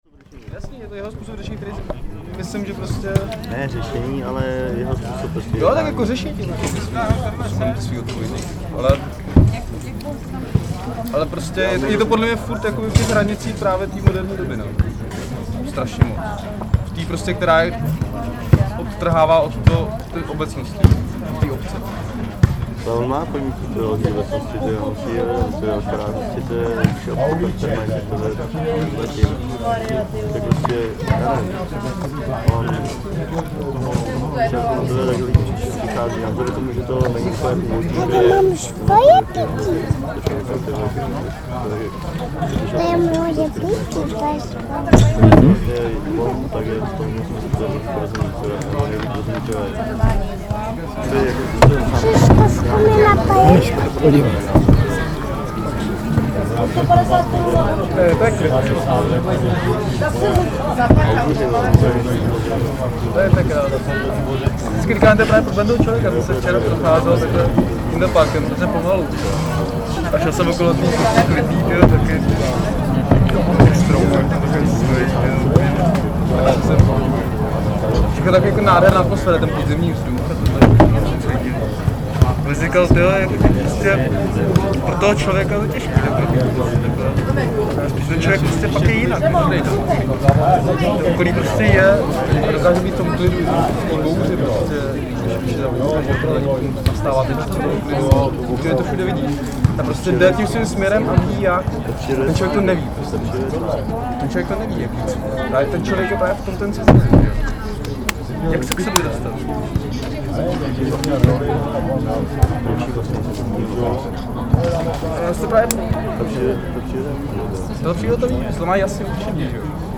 field recordings, sound art, radio, sound walks
Tagy: exteriér lidé rozhovory jídelny sport
Zvuk nohejbalu v pozadí, poslouchám hovor dvojice vedle u stolu v libeňské polostrovní hospodě U Budyho, kde údajně sídlí redakce studentského časopisu pro seniory Babylon. Zahradní hospoda sestává z dřevěné boudy v zahrádkářské kolonii, k dispozici jsou volejbalové a nohejbalové kurty.